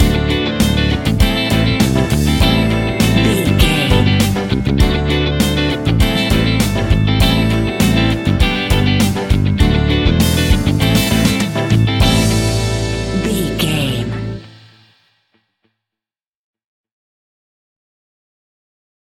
Uplifting
Aeolian/Minor
pop rock
fun
energetic
acoustic guitars
drums
bass guitar
electric guitar
piano
organ